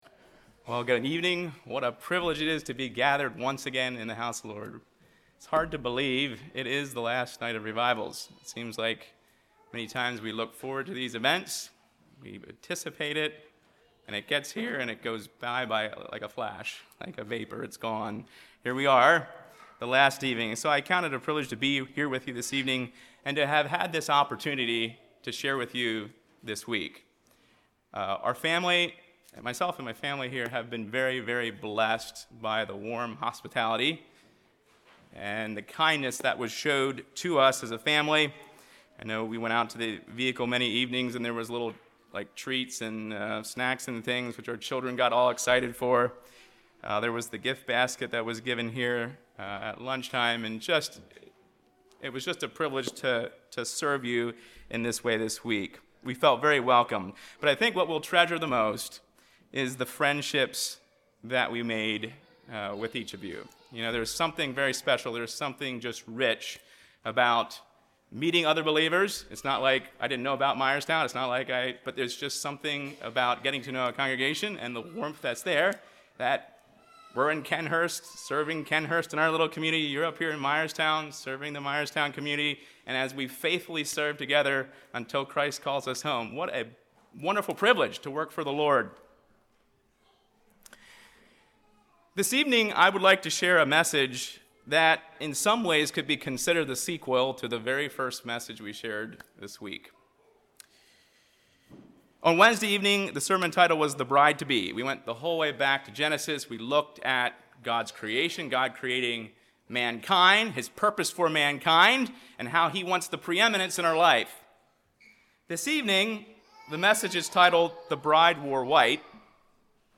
2025 Revivals Service Type: Revivals « Conservative Anabaptist in A Culture of Tolerance & Inclusion